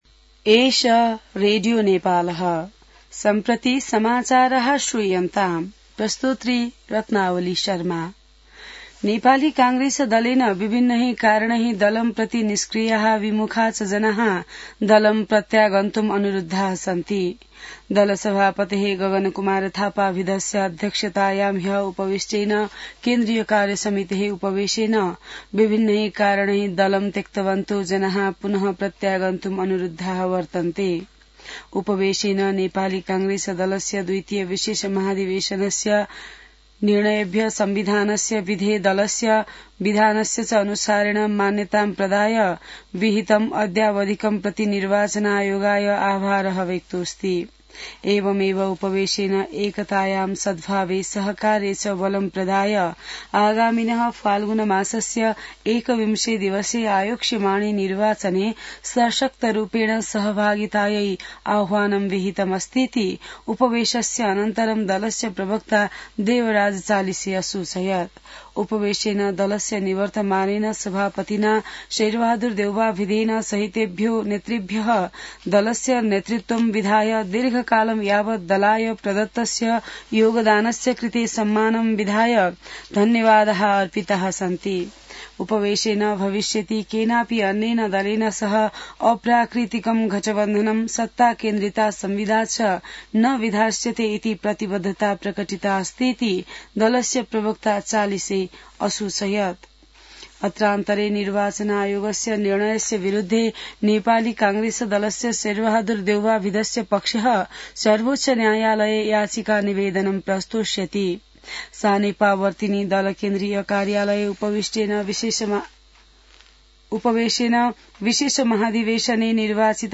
संस्कृत समाचार : ४ माघ , २०८२